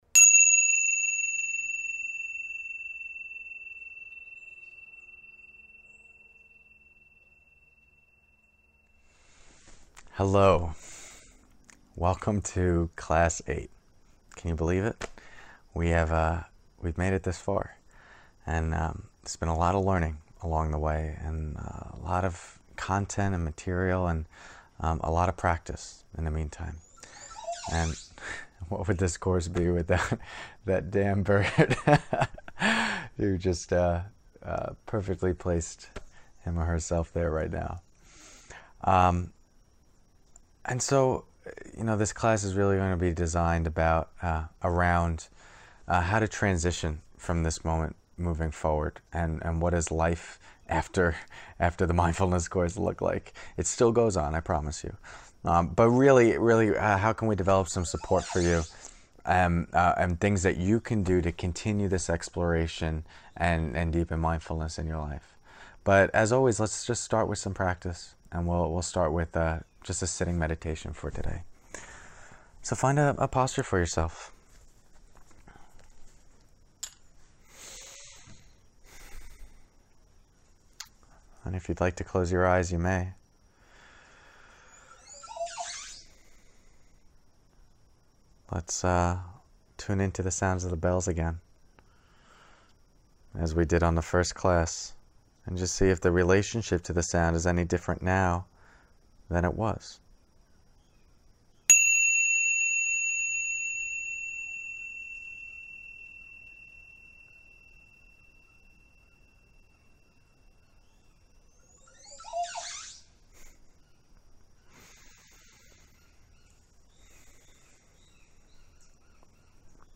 Week 1 Week 2 Week 3 Week 4 Week 5 Week 6 Week 7 Week 8 Bonuses Week 8: Integrating Mindfulness Into Your Life Session 8.1: Final Sitting Meditation Together